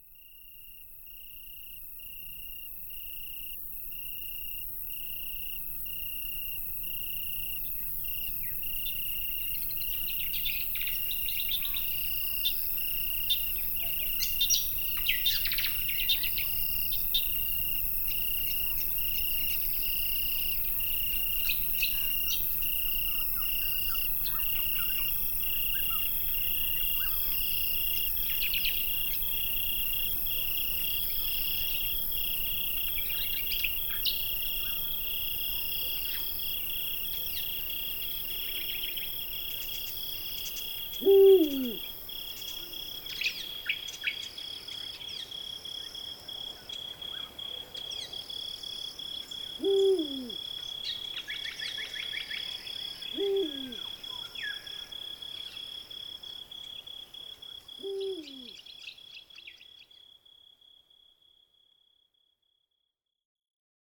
Enjoy a melodic one-hour soundscape of frogs, crickets, birds, and other wild creatures.
These inspiring sounds of nature are perfect for study time, creative time, or relaxation.
sounds of nature, relaxation, inspiration, Yoga, meditation, Study, playtime, Creative, relax